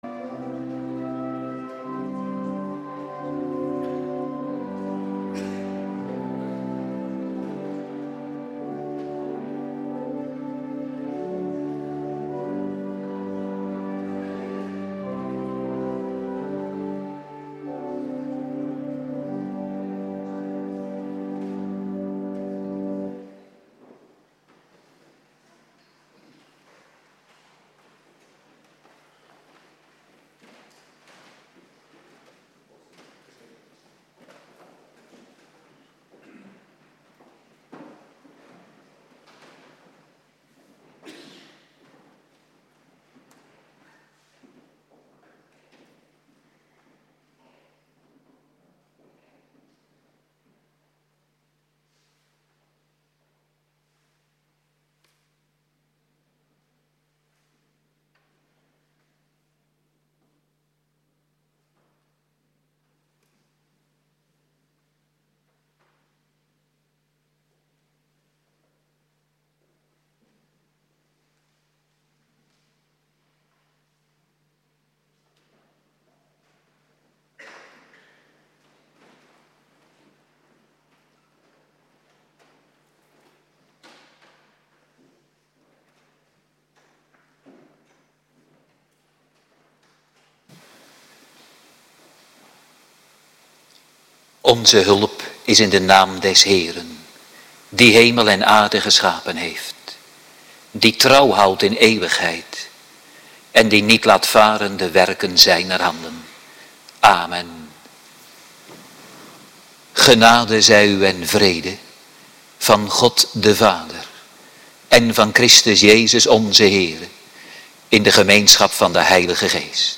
Avonddienst Voorbereiding Heilig Avondmaal
19:30 t/m 21:00 Locatie: Hervormde Gemeente Waarder Agenda